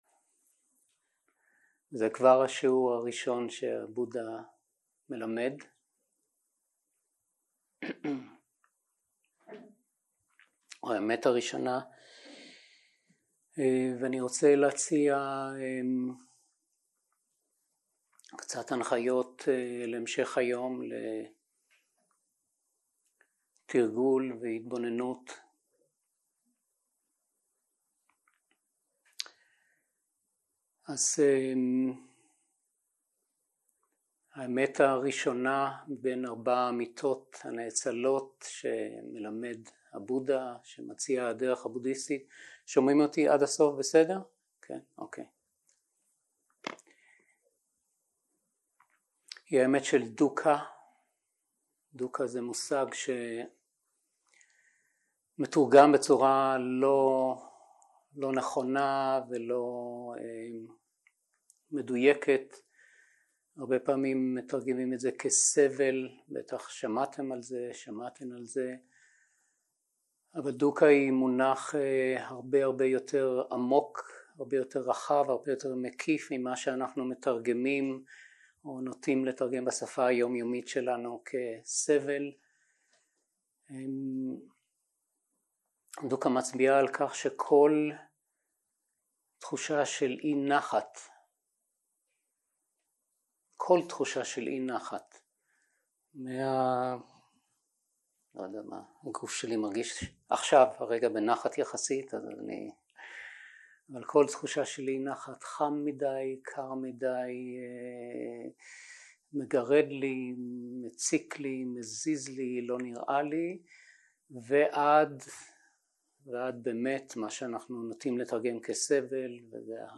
יום 2 - בוקר - הנחיות למדיטציה והנחיות להליכה - שני החיצים - הקלטה 2 0:00 0:00 סוג ההקלטה: סוג ההקלטה: שיחת הנחיות למדיטציה שפת ההקלטה: שפת ההקלטה: עברית